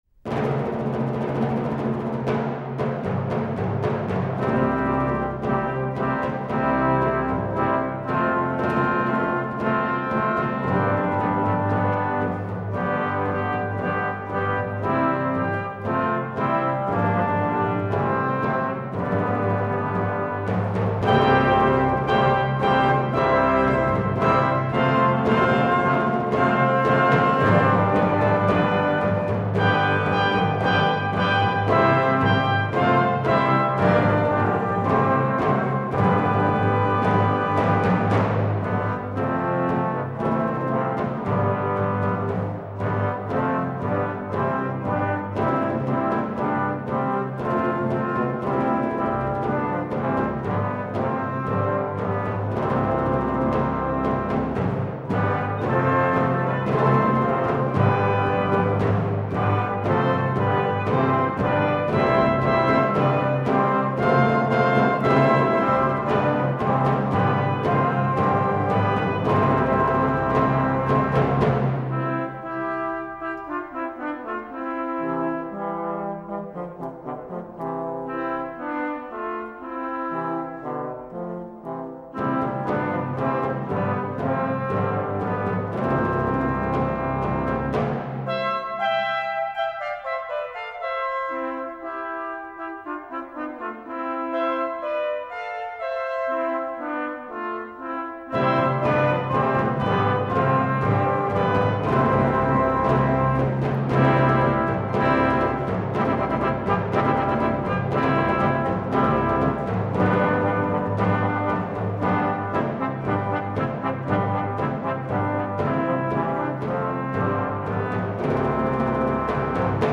Orquesta
Danza